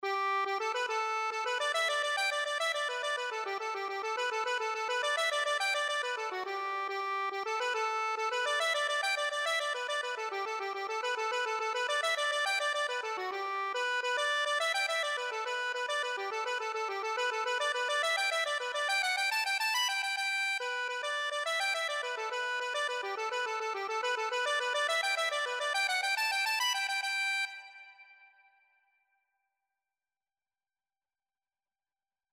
Accordion version
6/8 (View more 6/8 Music)
G major (Sounding Pitch) (View more G major Music for Accordion )
Accordion  (View more Intermediate Accordion Music)
Traditional (View more Traditional Accordion Music)
Irish